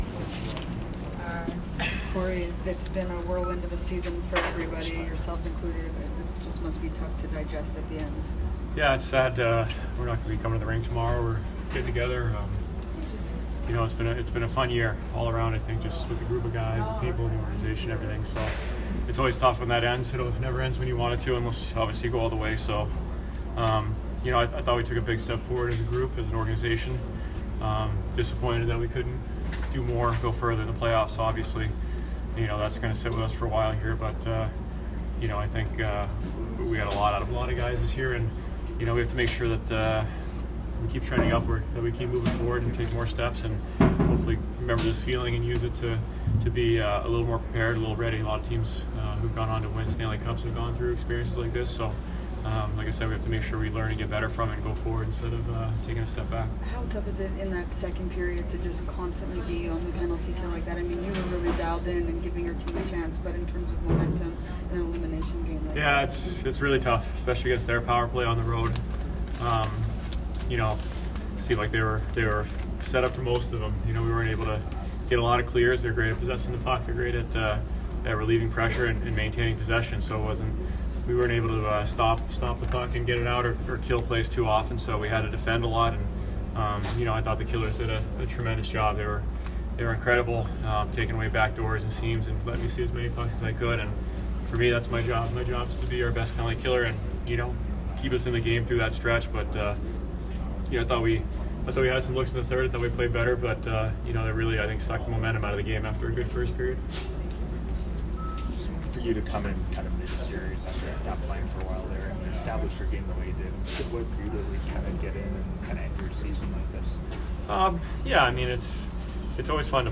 Cory Schneider post-game 4/21